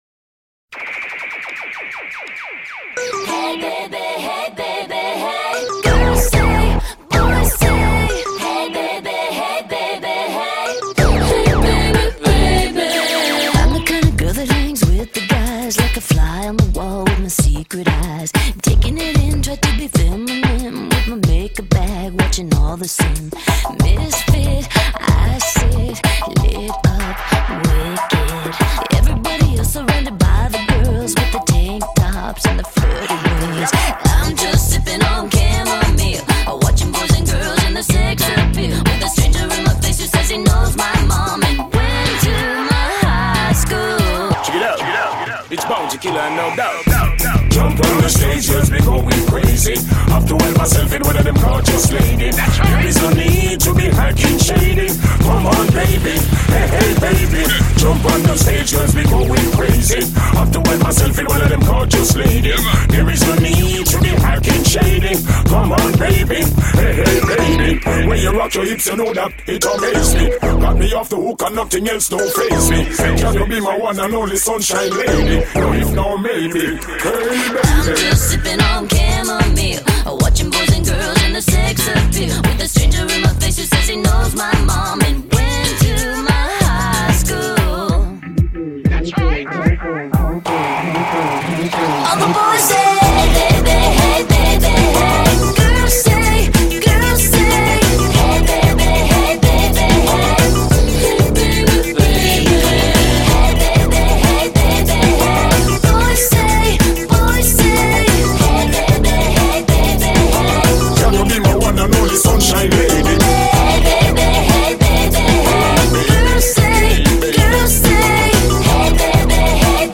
BPM187
Audio QualityCut From Video